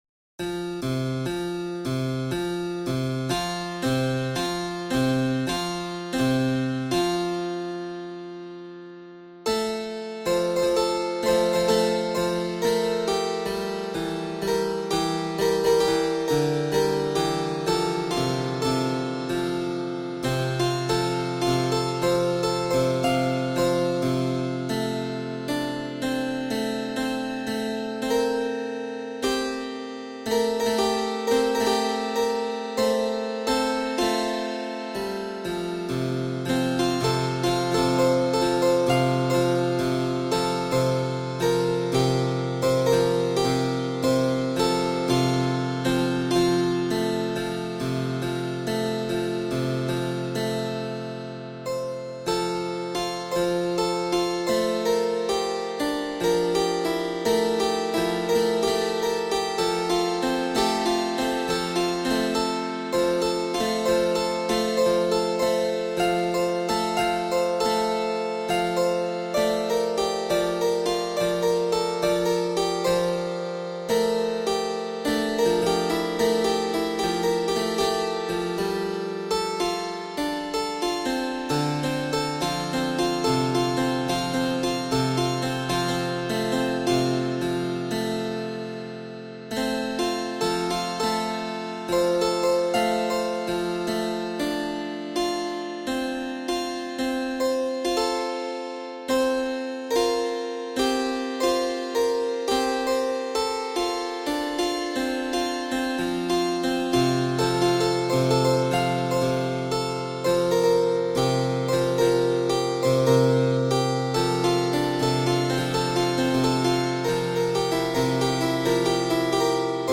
Musique, genre baroque-épisode classique avec un appareil de circonstance pour jouer toutes sortes de sons polyphoniquement, allant des percussions aux timbres plus sophistiqués, genre synthétiseur ou...